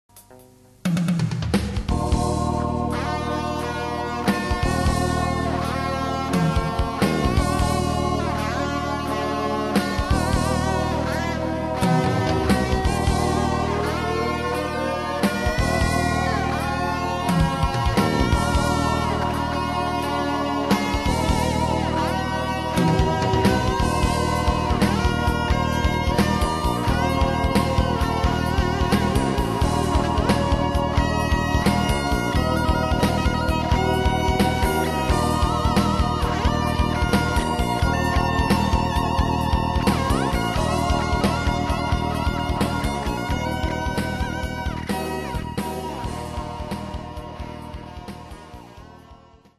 感想　えらい自信やなあ(^^;)、、、後に「グレート・アトラクター」としてライブで披露した曲です(^^o)。イントロのテーマが浮んだときは、自分でも鳥肌が立ったのを覚えています。まだモチーフの段階ですが完成後、kehellのライブでハイライト曲としてよく演奏していました。